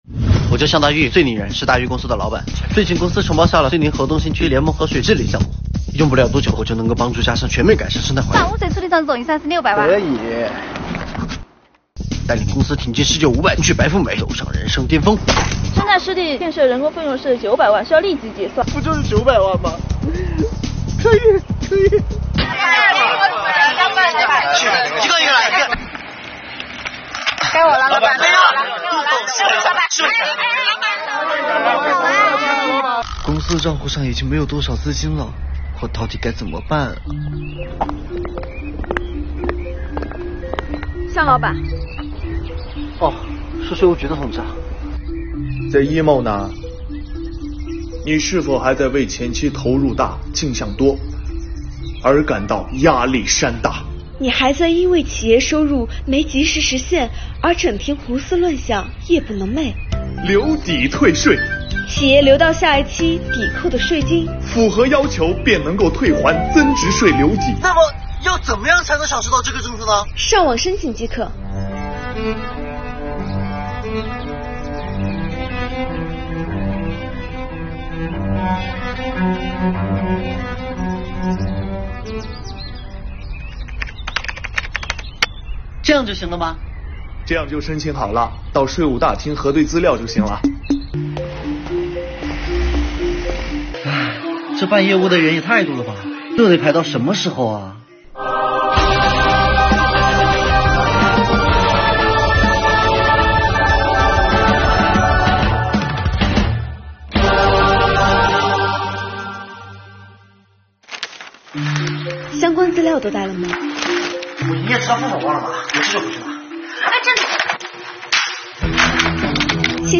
作品风格活泼，画面幽默诙谐，搭配悠闲欢快的音乐，能够激起观看兴趣，用轻松愉快的方式宣传了增值税留抵退税给企业带来的实惠及税务部门人性化的服务举措。